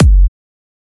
edm-kick-23.wav